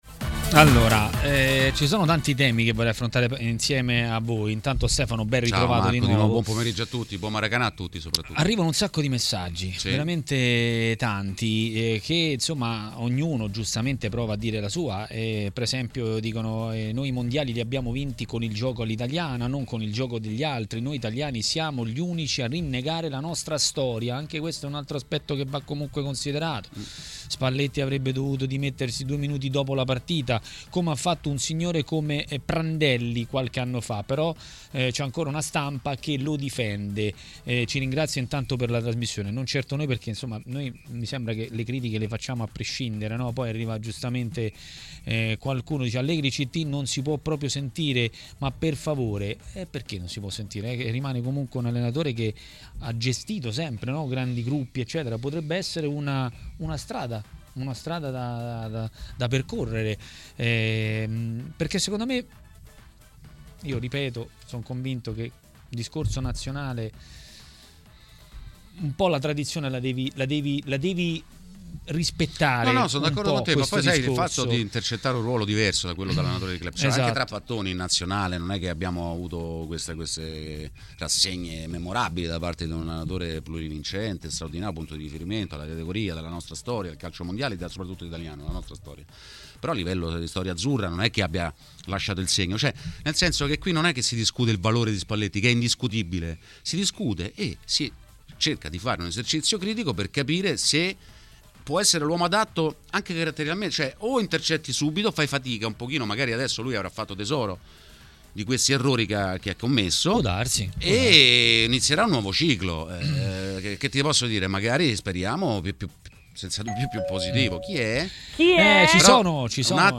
A dire la sua sui temi del giorno a TMW Radio, durante Maracanà, è stato l'ex calciatore Massimo Orlando.